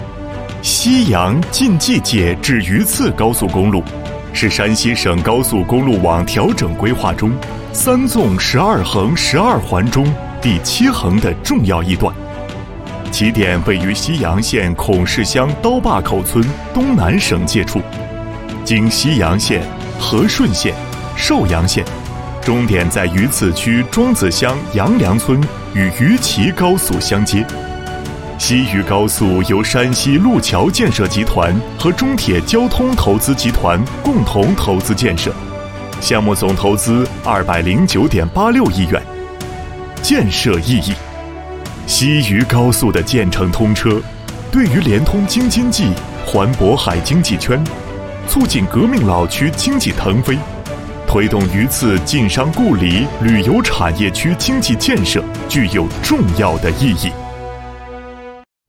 B男11号 | 声腾文化传媒
【专题】政府汇报(自然大气) 高速公路
【专题】政府汇报(自然大气) 高速公路.mp3